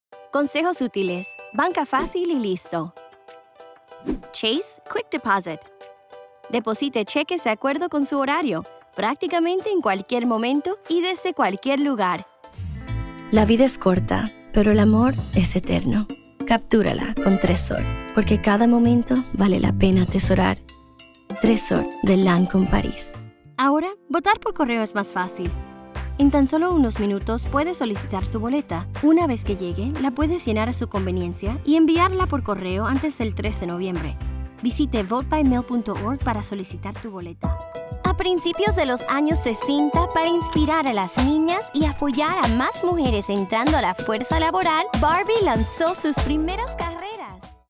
Child, Teenager, Young Adult, Adult
Has Own Studio
spanish latin american | natural
COMMERCIAL 💸